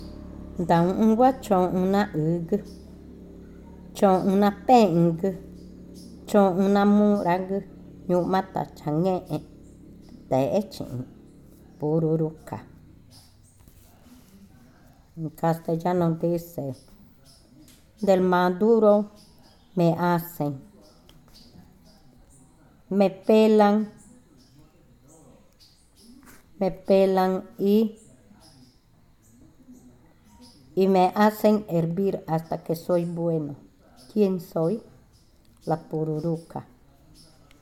Adivinanza 30. Pururuca
Cushillococha